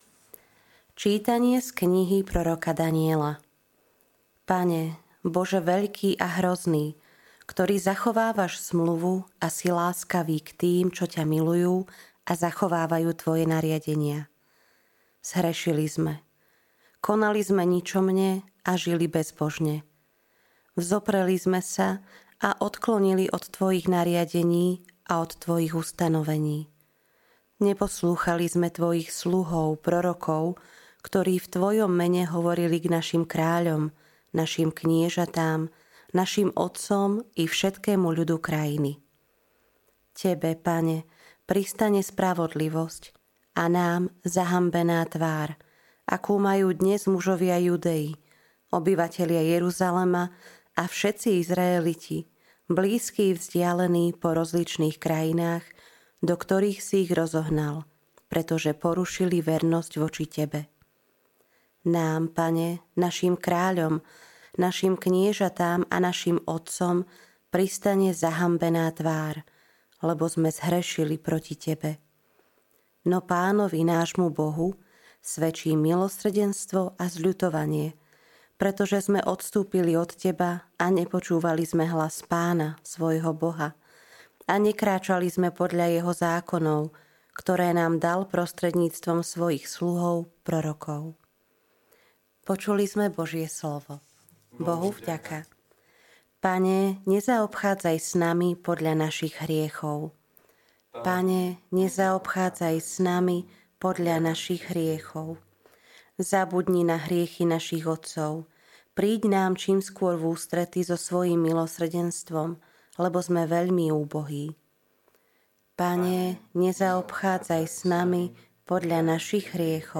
LITURGICKÉ ČÍTANIA | 17. marca 2025